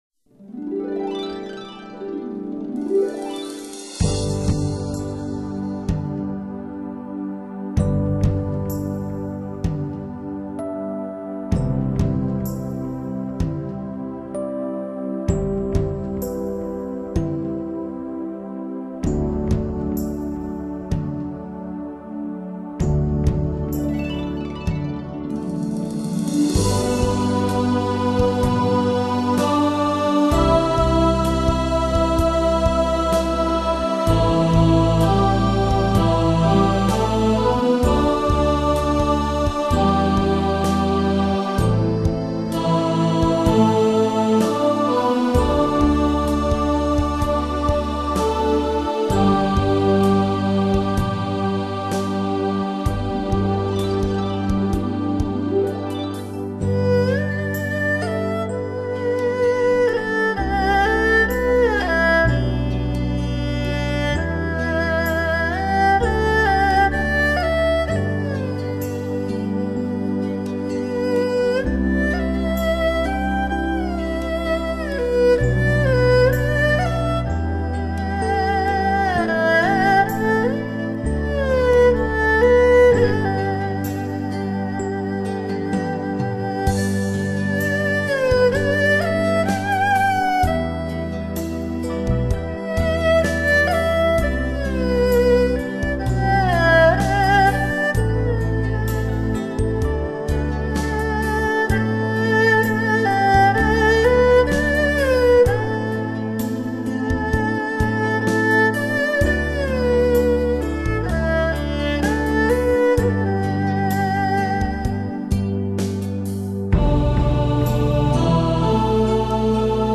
六声道的DTS-CD效果，将带给你更完美的听觉感受，收藏本专辑的朋友定能体验到它的珍贵。